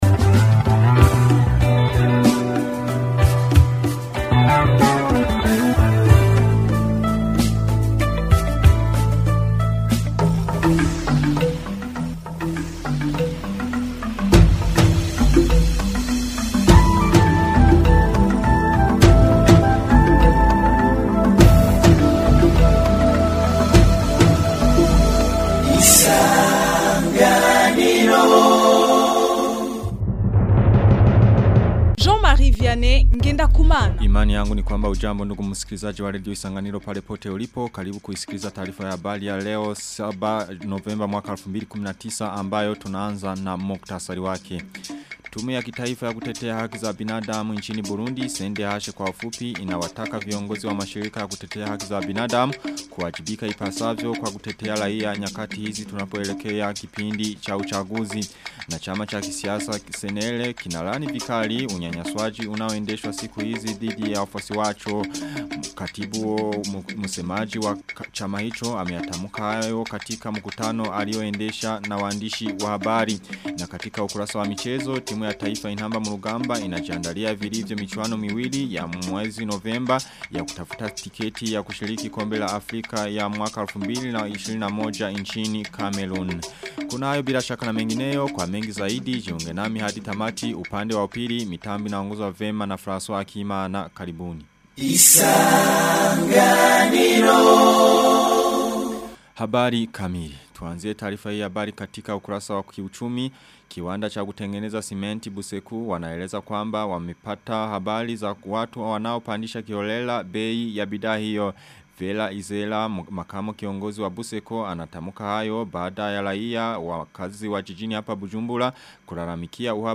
Taarifa ya habali tarehe 7 novemba 2019